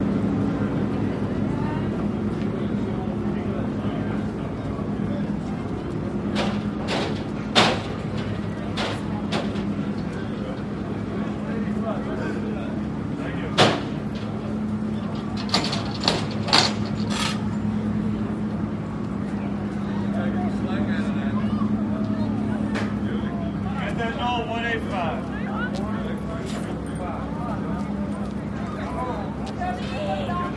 Harbor Ambience, People, Some Clanks